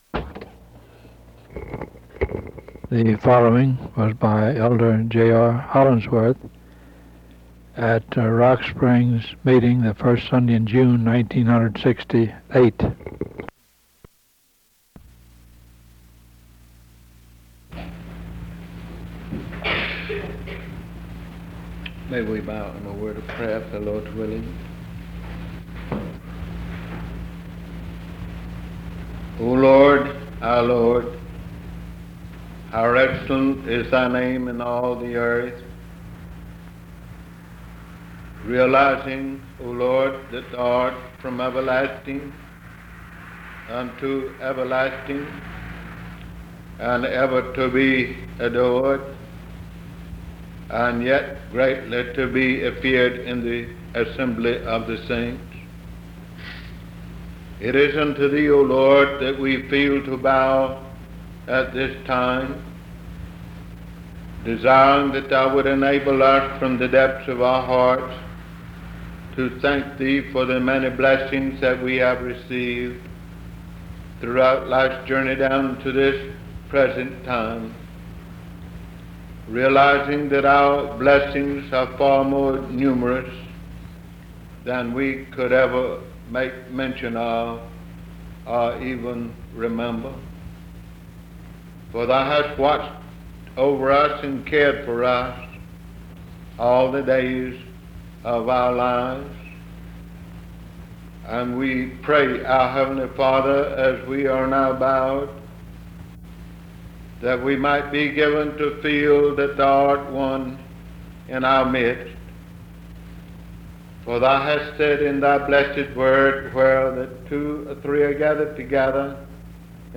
Sermon
at Rock Springs Church